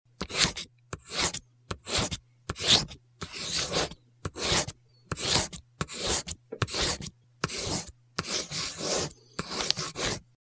Звуки карандаша
Шуршание карандаша по бумаге